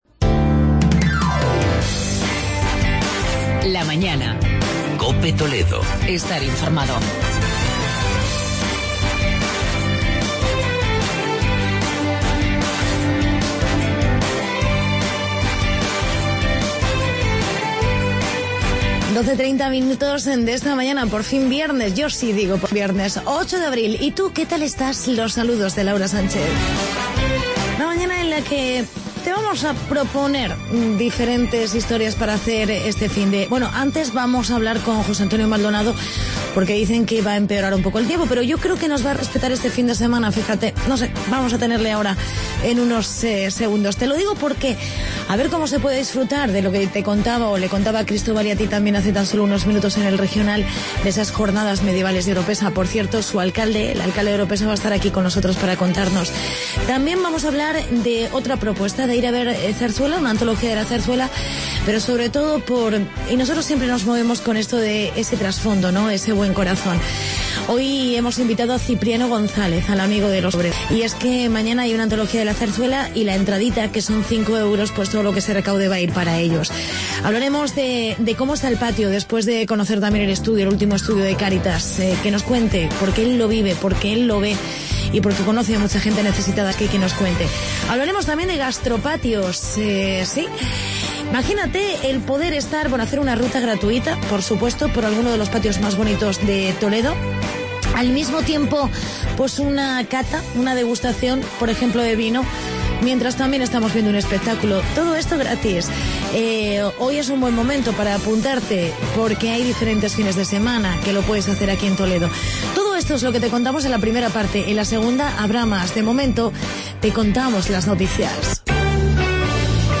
Reportaje sobre "Gastropatios" y entrevista con el alcalde de Oropesa, Juan Antonio Morcillo por las XVII Jornadas Medievales.